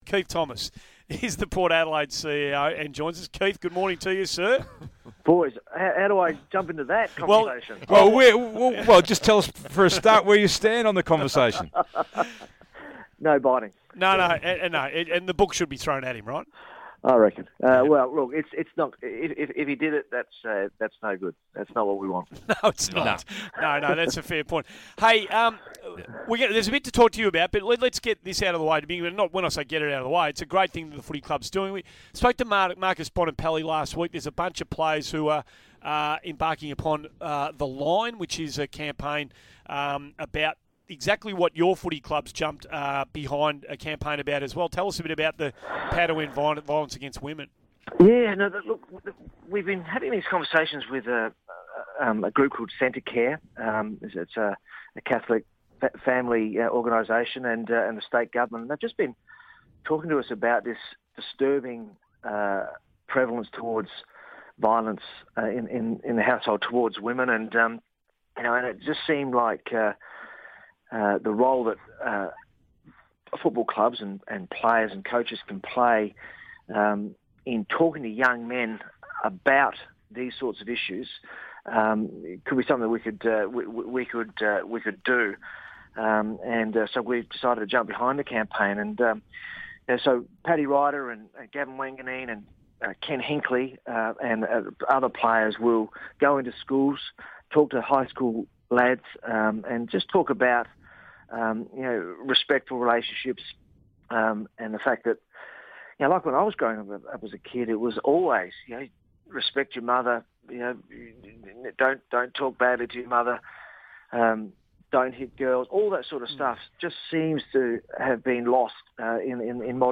speaks to the guys on Morning Glory about the club's campaign to reduce violence against women